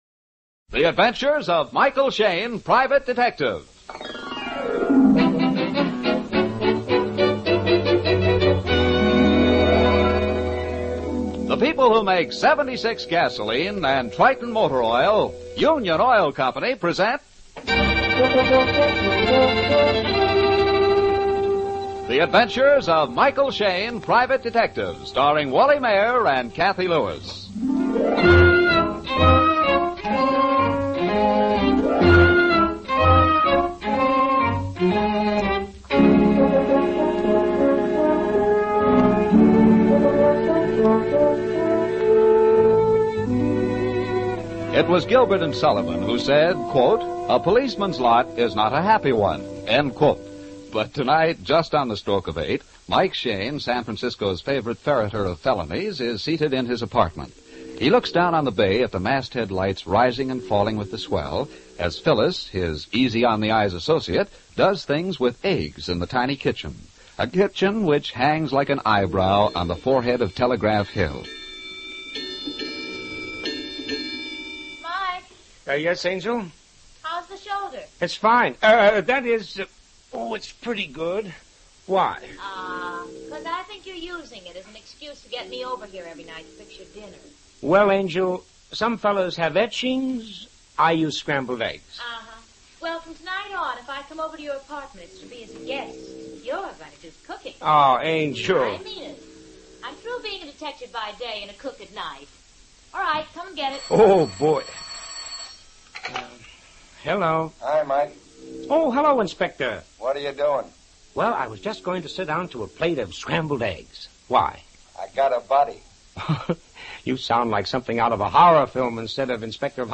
Michael Shayne 450820 Red Lead Mystery, Old Time Radio